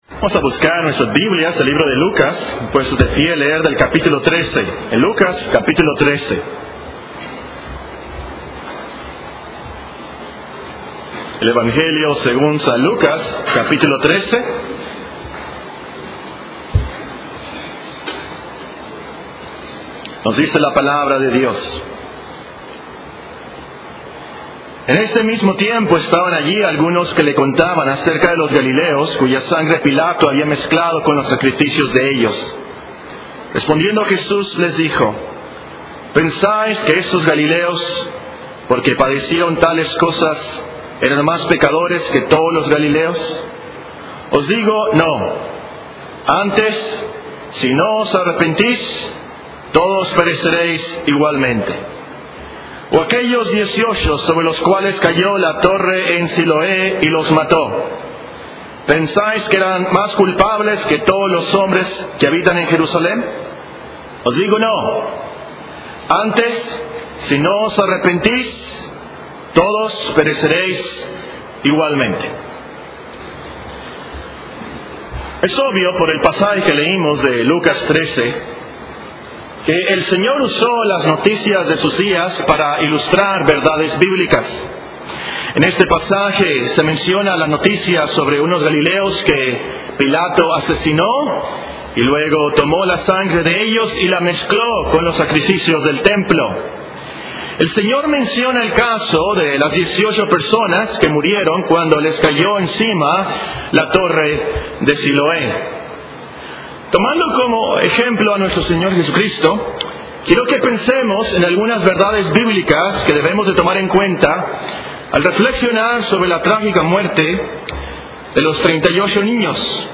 Serie de sermones General